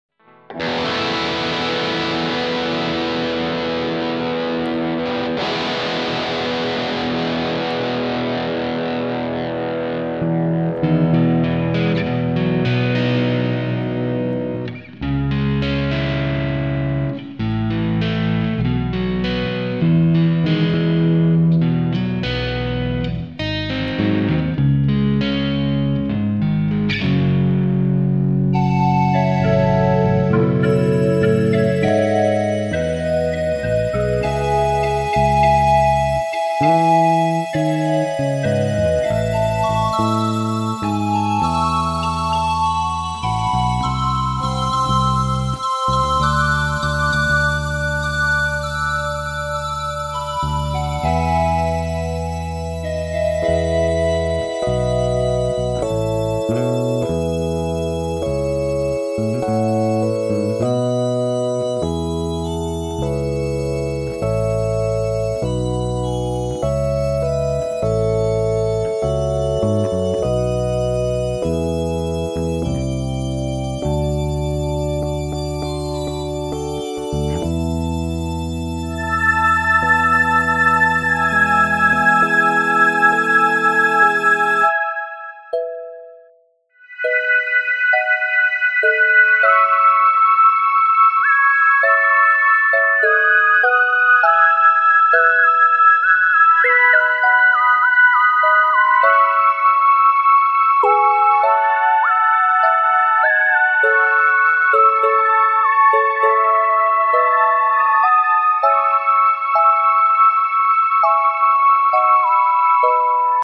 ベースも自分で弾いて録りました。
今回もリードギターは抜いてありますが、完成品にはちゃんと入ってます。